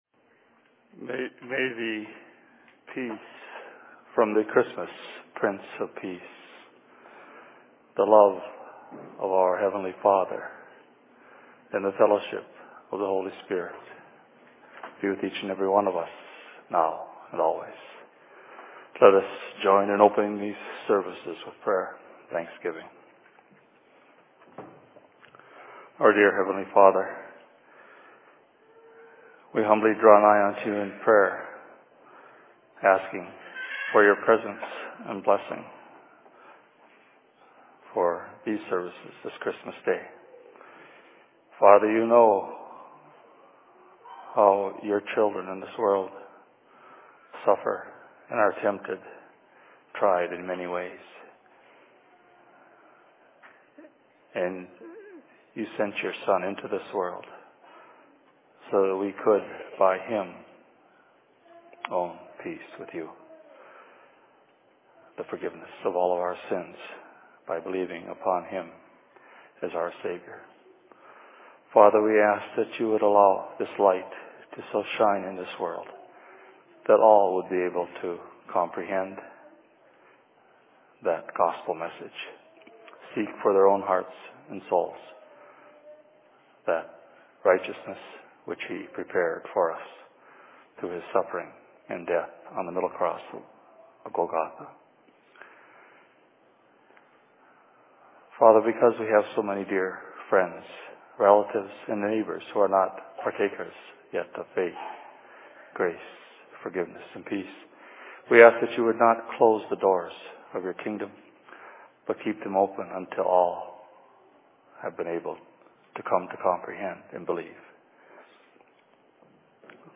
Sermon in Minneapolis 25.12.2011
Location: LLC Minneapolis